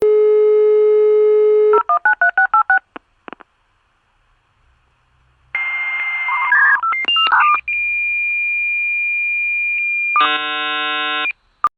Failed Dial Up Modem
SFX
yt_RzR5kUlOo0Q_failed_dial_up_modem.mp3